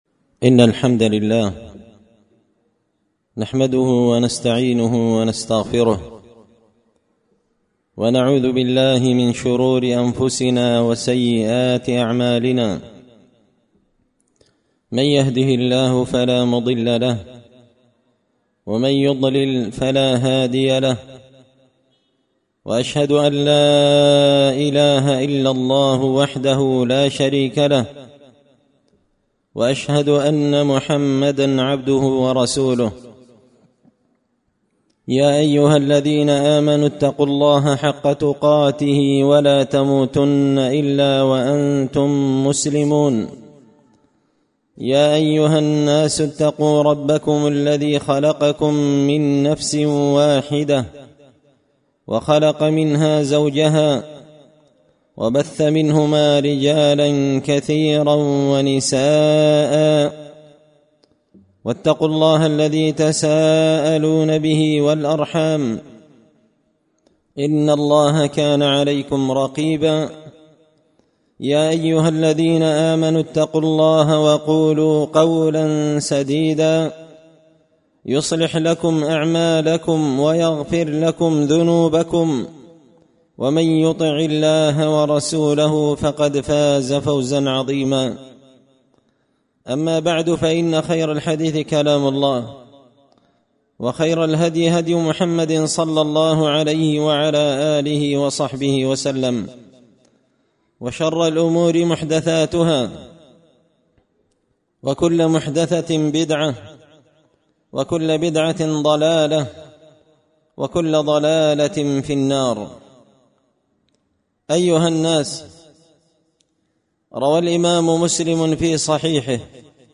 خطبة جمعة بعنوان – الخشوع وأحسان الوضوء في الصلوات مكفر للسيئات
دار الحديث بمسجد الفرقان ـ قشن ـ المهرة ـ اليمن